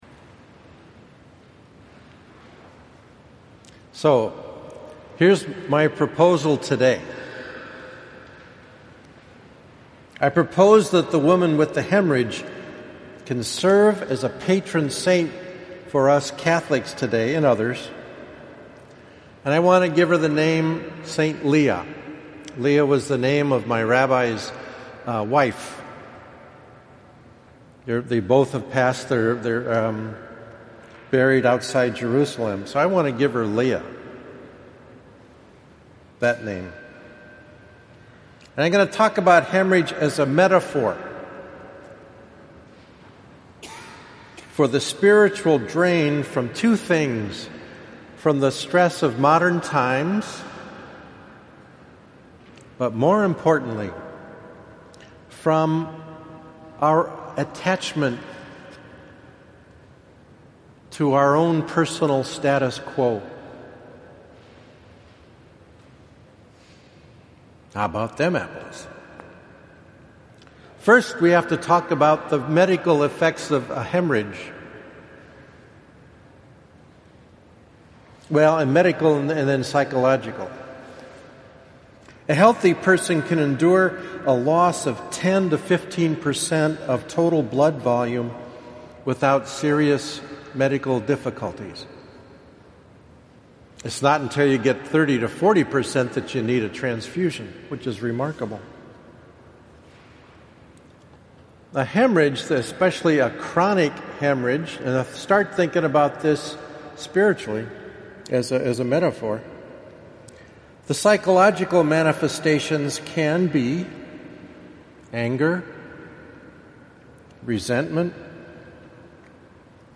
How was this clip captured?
Thirteenth Sunday of Ordinary Time (11:30 am)